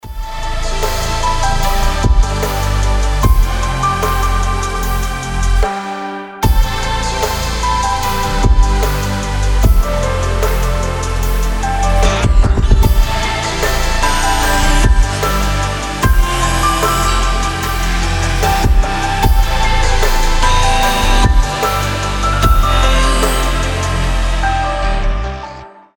• Качество: 320, Stereo
атмосферные
Electronic
медленные
расслабляющие
chillstep
Нереально атмосферная музыка для будильничка